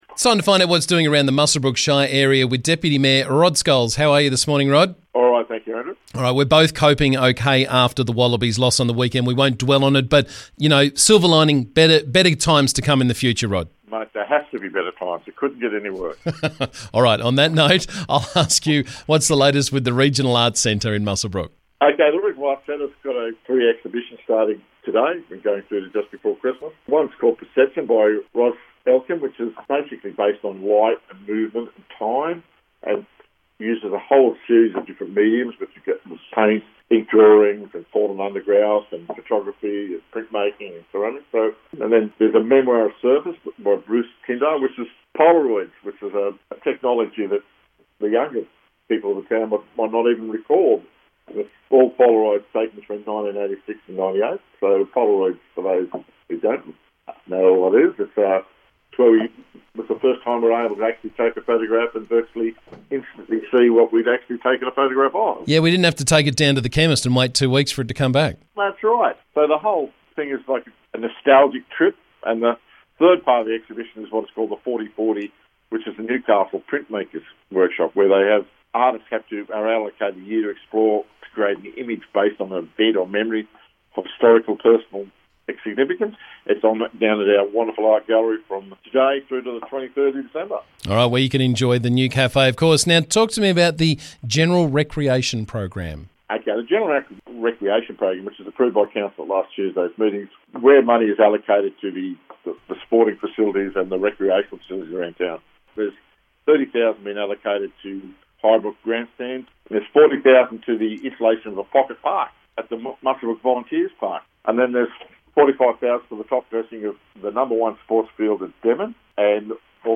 Muswellbrook Shire Council Deputy Mayor Rod Scholes joined me to talk about the latest from around the district.